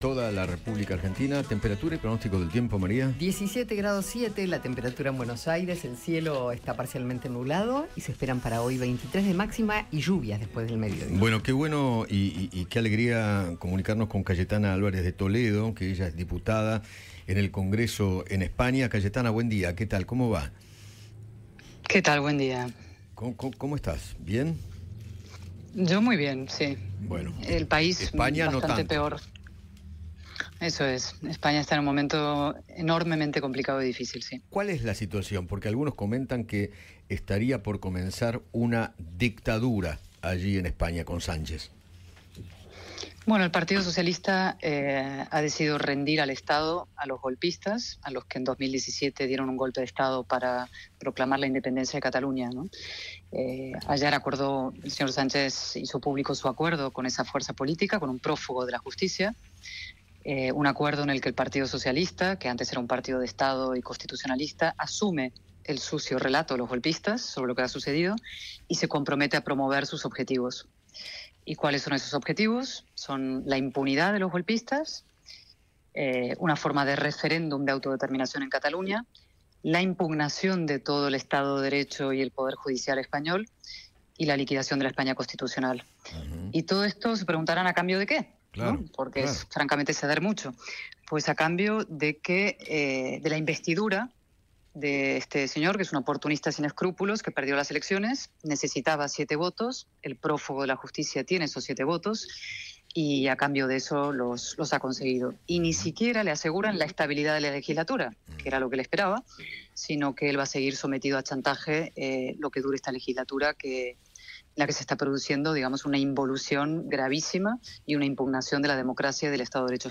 Cayetana Álvarez de Toledo, periodista y diputada española, habló con Eduardo Feinmann sobre la situación política que vive su país.